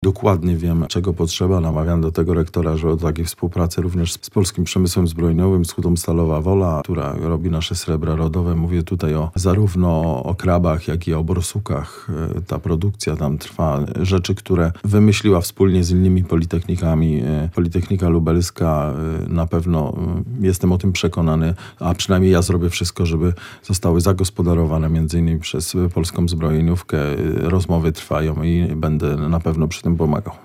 Między innymi o współpracy uczelni z przemysłem zbrojeniowym rozmawiał dziś w Lublinie minister nauki i szkolnictwa wyższego dr Marcin Kulasek.
Minister gościł na Politechnice Lubelskiej.